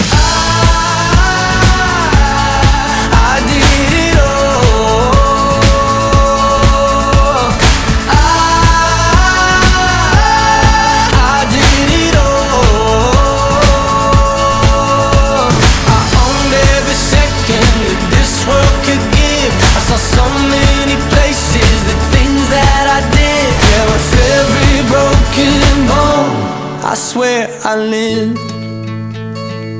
• Качество: 192, Stereo
indie pop
indie rock